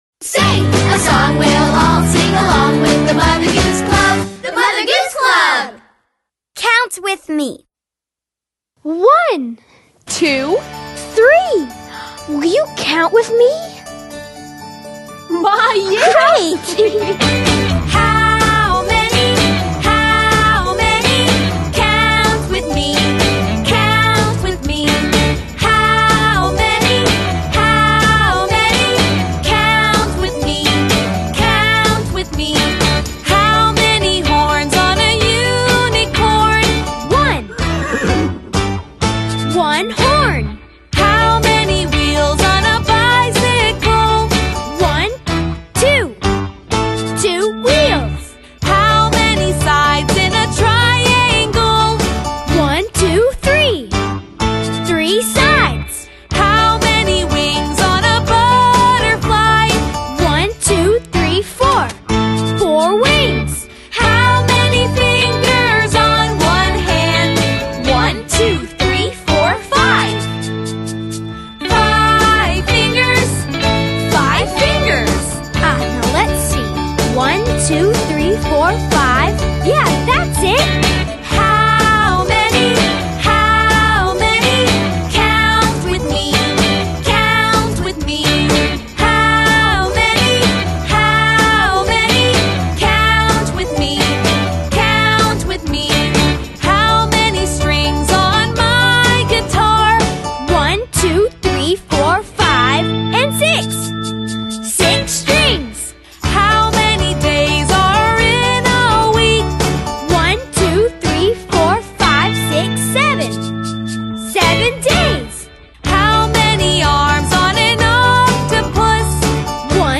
Замечательная обучающая песня для учащихся 2 класса.